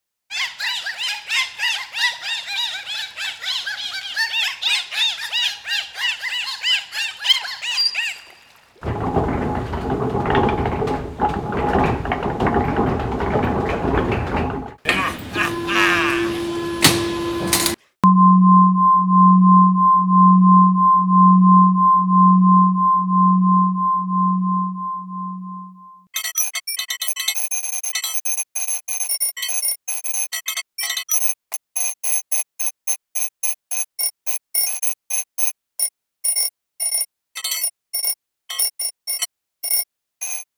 Un corpus de son a été élaboré, en fonction des entrées suivantes : naturel/vocal (humain/animal), industriel/mécanique, synthétique/électroacoustique, signaux de danger typiques de films d'horreur, alarmes automobiles réelles et "scènes auditives" comprenant des enregistrements ou des synthèses de sons individuels complexes. Les sons ont été selectionnés soit à partir de bases de données sonores commerciales ou d'enregistrements-terrain d'environnements sonores urbains, soit générés ou transformés par divers techniques de synthèse/transformation réalisées dans AudioSculpt, CataRT, AudioGuide ou Max/MSP.
Et ci-joint, la version actuelle de l'échantillonnage du corpus de sons :
ALARM_CORPUS_MEDLEY.mp3